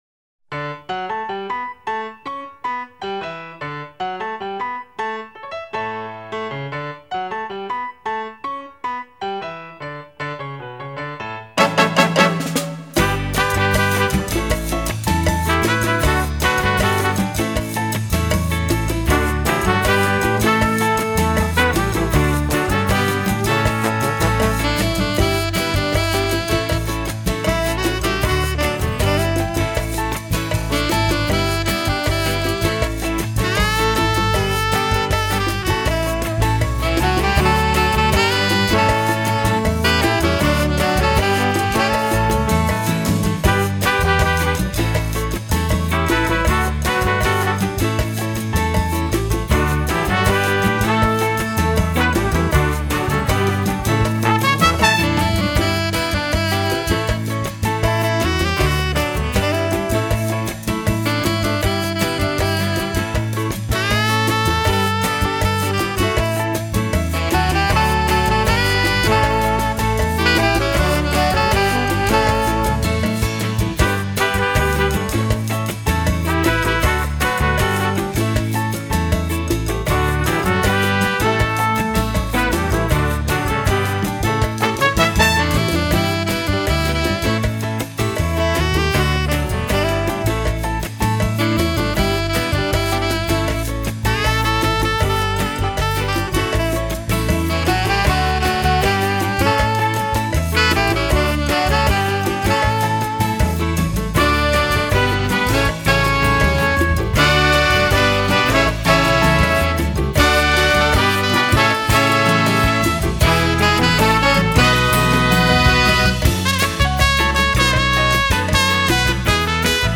Genre:Latin
喇叭、鼓、吉他、键盘等各式乐器精采纷呈的轮番上阵或是互相热情对话
曼波、恰恰、骚沙、康加等舞曲旋律将连连诱动你的肢体，随着音乐节拍情不自禁起舞跃动，上瘾的滋味要你一起忘我拉丁舞音里！